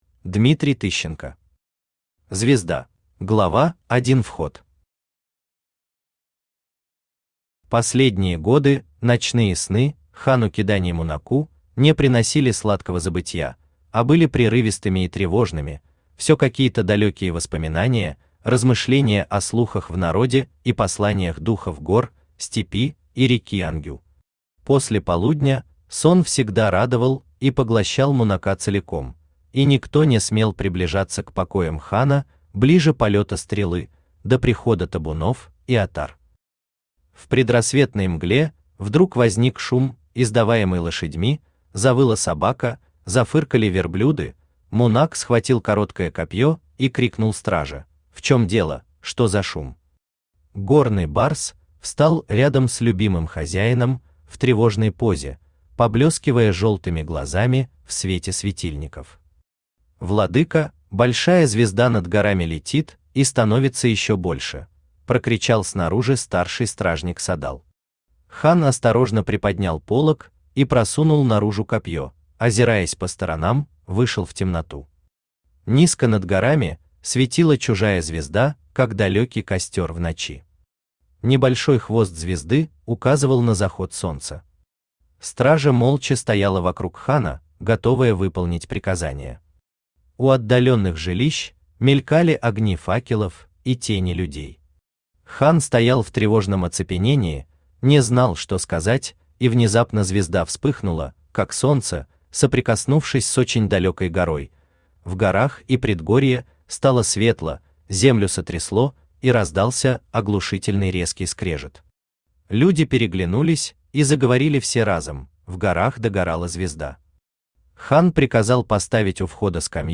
Aудиокнига Звезда Автор Дмитрий Тыщенко Читает аудиокнигу Авточтец ЛитРес.